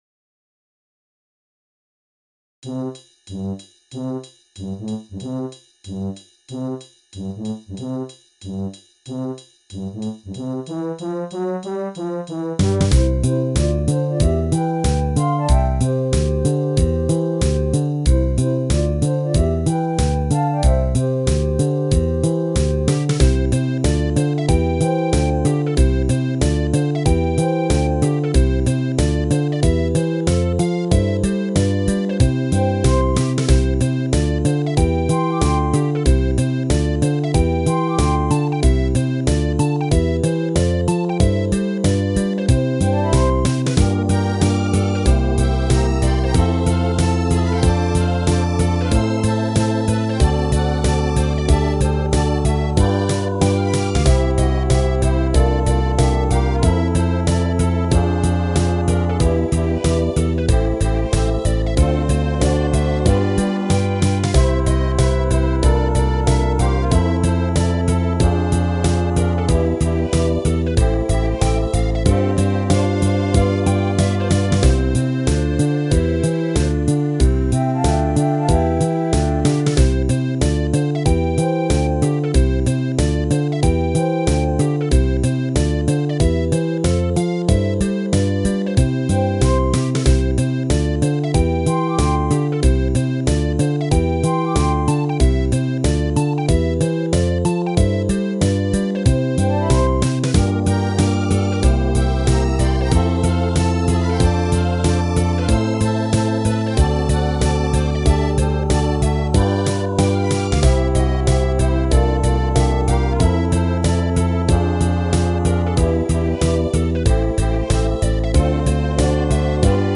サビ前の映画音楽風のストリングスはテキトーに掘り込んだ。